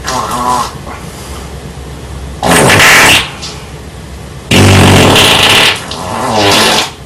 Cirno’s Wet Fart